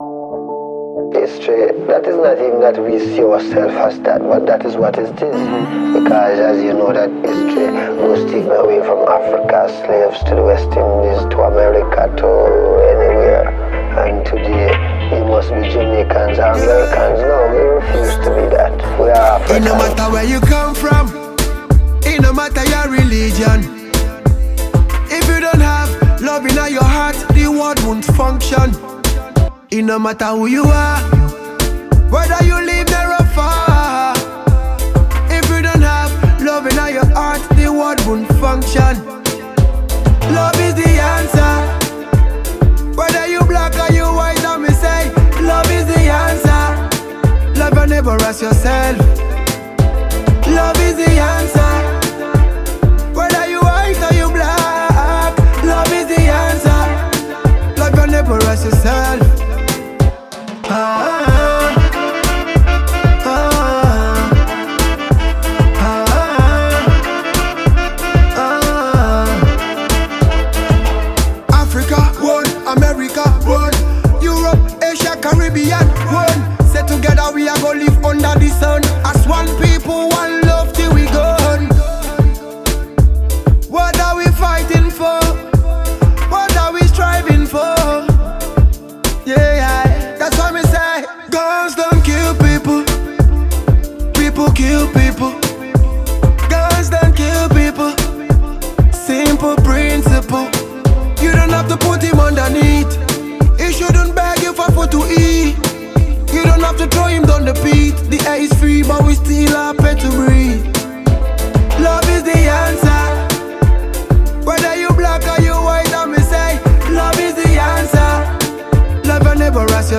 Talented Nigerian Dance Hall veteran